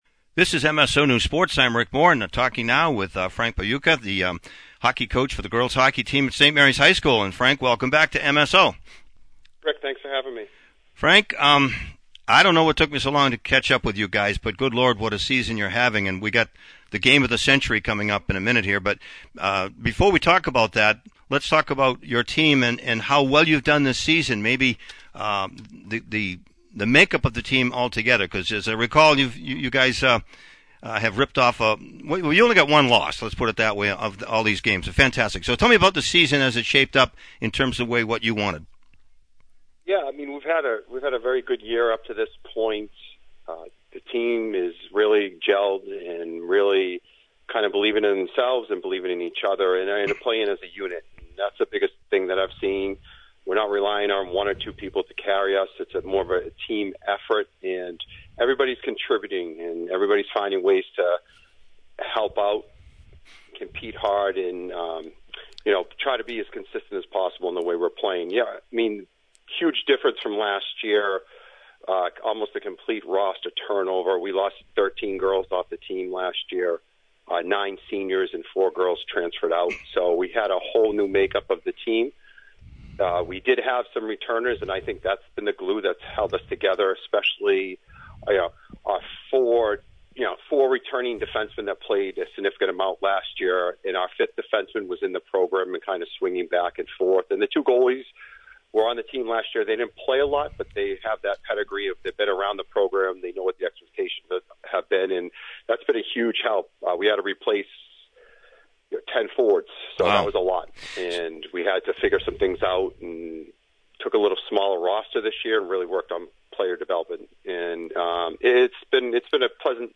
(Audio, Links) Post-game, Pre-game